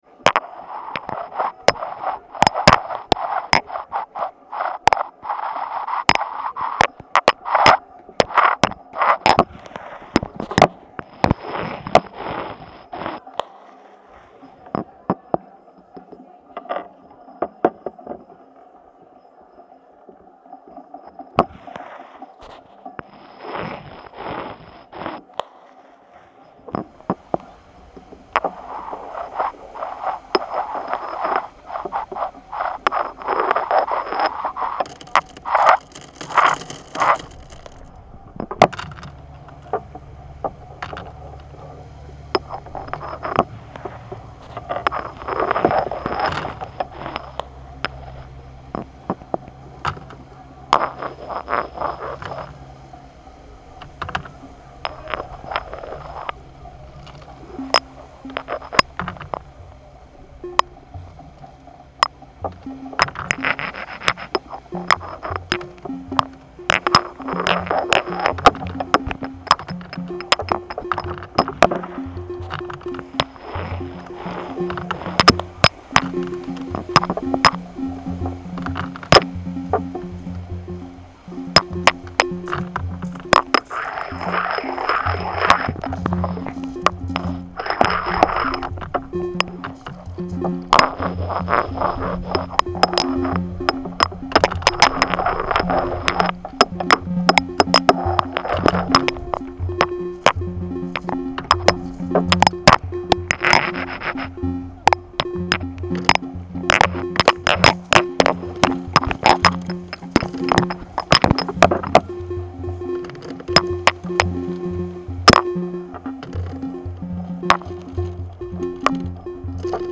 The audience are invited to write or sound onto a pebble with the pen stone, keeping the pebble afterwards. A contact mic attached to the pen stone amplifies the rocky scrapes, swooshes and knocks referencing the invisible shapes being drawn.
These live sounds are added to a generative composition using the audio program Supercollider 3 and include an ambient musical aspect inspired by slot machine sounds from a family amusement arcade near where the pebbles were collected.
The pebbles were collected from Newlyn beach where the artist also performed and recorded an audio work involving piling pebbles up on the beach and is periodically heard within the installation's composition.
stoneWriting.mp3